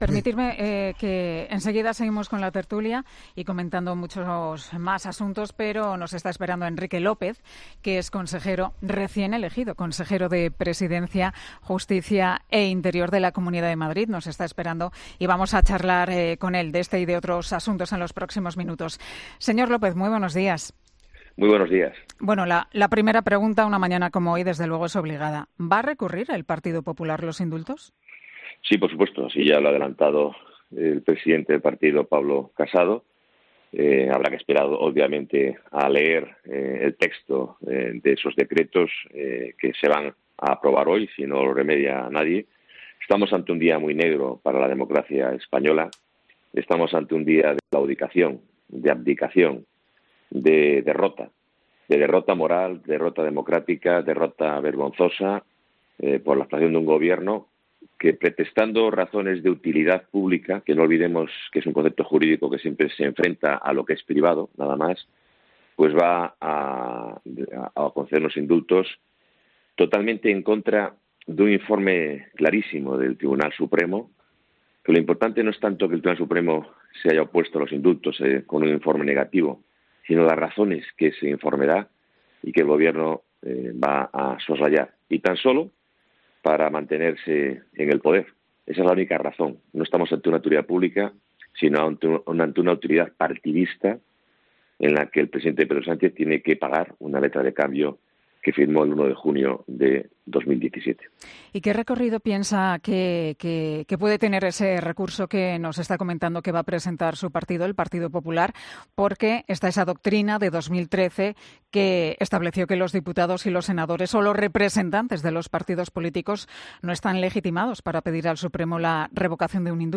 El consejero de Presidencia, Justicia e Interior de la Comunidad de Madrid habla sobre la concesión de los indultos del procés.